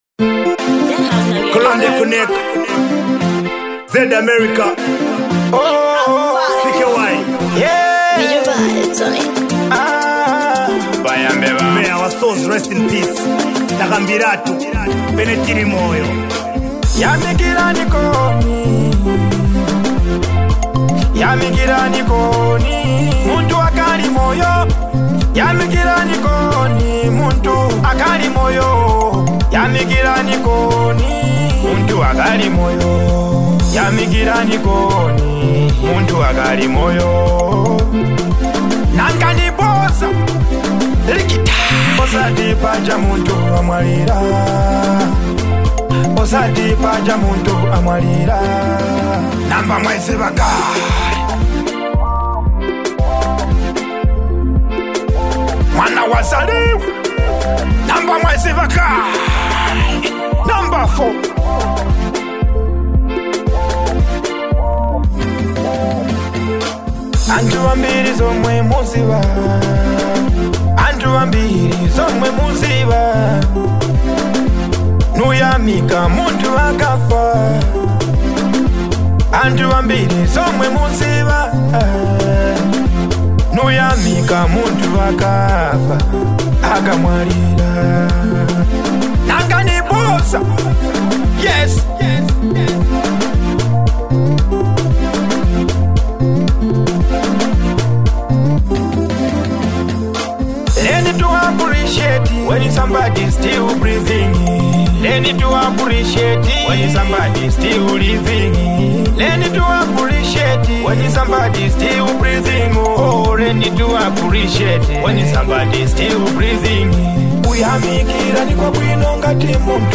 feel-good jam
a perfect blend of melody and street vibes.